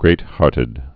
(grāthärtĭd)